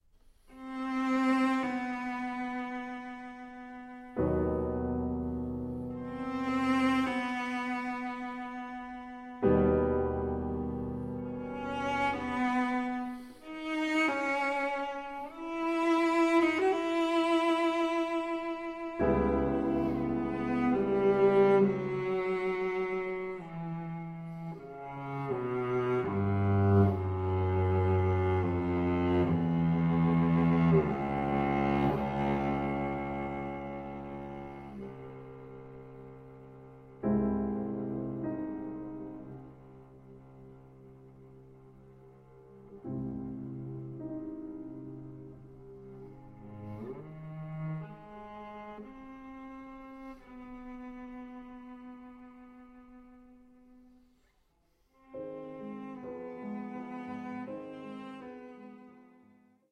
Works for cello & piano